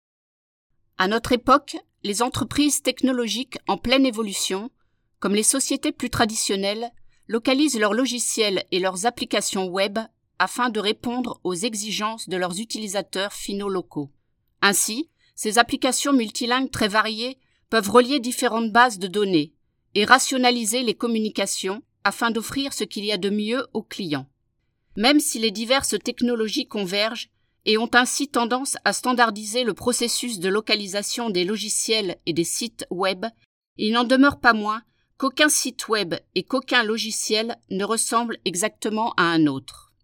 French Female 03998
NARRATION